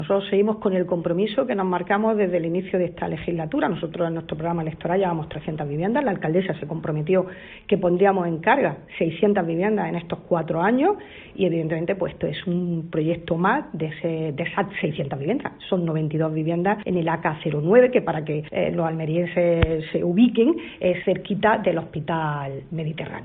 Eloísa Cabrera, concejal de Urbanismo de Almería.